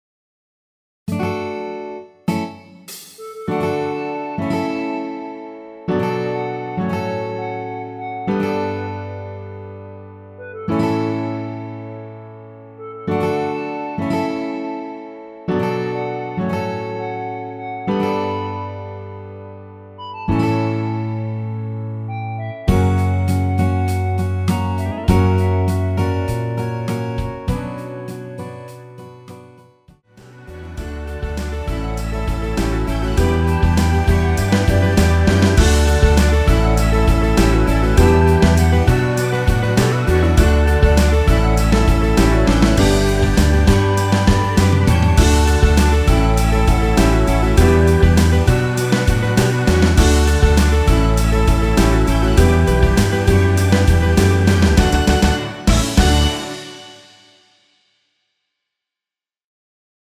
원키에서(-1)내린 멜로디 포함된 MR입니다.
전주 없이 시작 하는 곡이라 1마디 드럼(하이햇) 소리 끝나고 시작 하시면 됩니다.
멜로디 MR이란
앞부분30초, 뒷부분30초씩 편집해서 올려 드리고 있습니다.
중간에 음이 끈어지고 다시 나오는 이유는